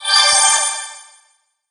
get_pickup_03.ogg